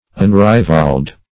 Unrivaled \Un*ri"valed\, a.